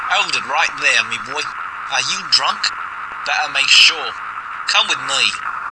Listen to the Cockney Cop